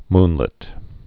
(mnlĭt)